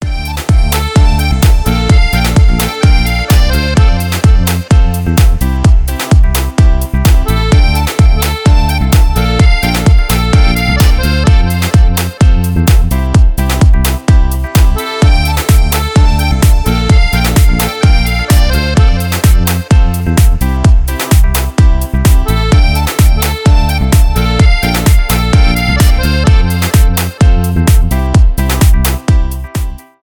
аккордеон
танцевальные
без слов